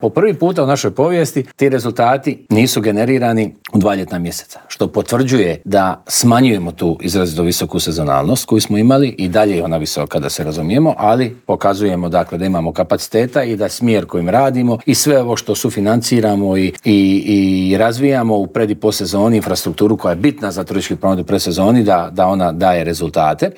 Inače, u prvih osam mjeseci došlo nam je 17,1 milijuna turista što je 2 posto više nego 2024. godine dok smo zabilježili i porast noćenja od 1 posto te ostvarili 89,9 milijuna noćenja o čemu smo u Intervjuu tjedna Media servisa razgovarali s ministrom turizma i sporta Tončijem Glavinom.